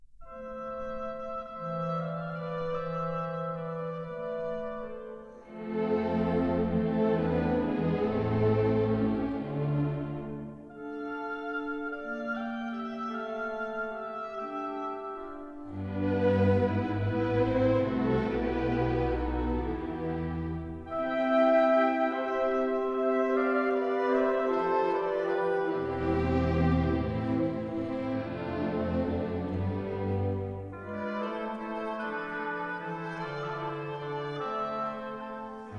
Stereo recording made in London